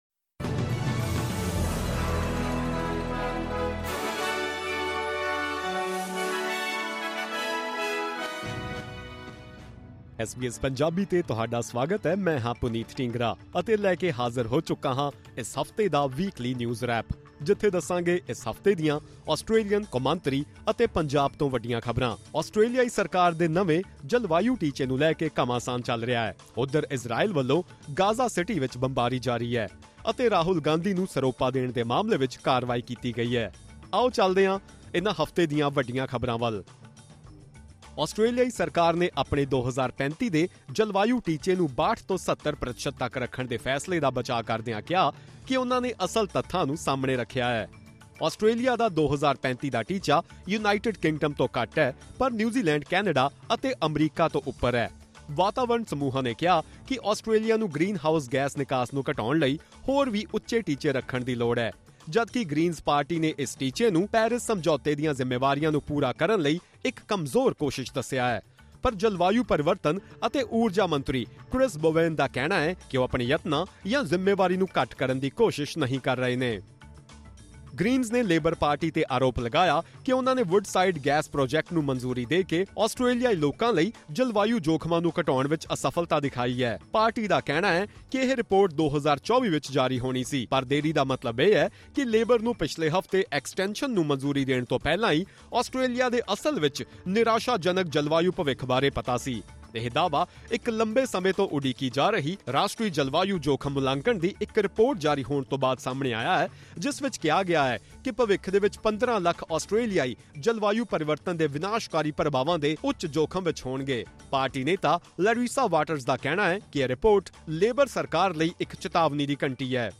Top News of the week in Punjabi.